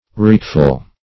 Search Result for " wreakful" : The Collaborative International Dictionary of English v.0.48: Wreakful \Wreak"ful\, a. Revengeful; angry; furious.
wreakful.mp3